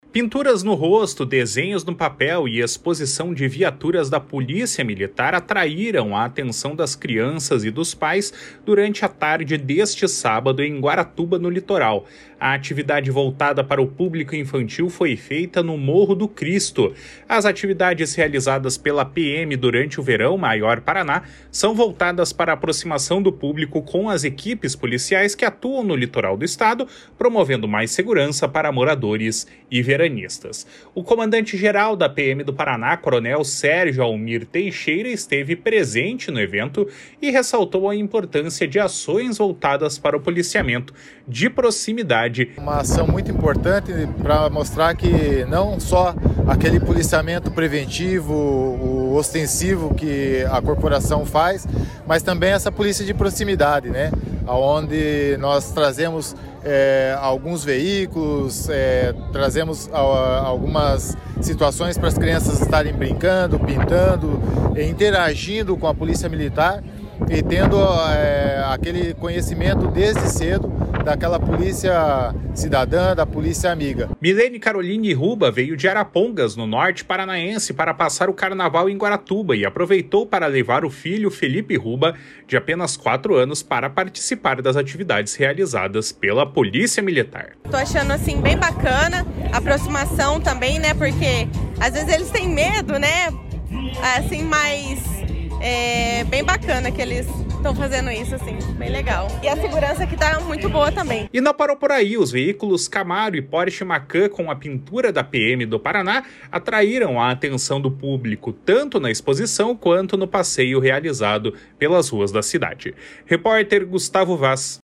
O comandante-geral da Polícia Militar do Paraná, coronel Sérgio Almir Teixeira, esteve presente no evento e ressaltou a importância de ações voltadas para o policiamento de proximidade realizadas pelos militares estaduais. // SONORA SÉRGIO ALMIR TEIXEIRA //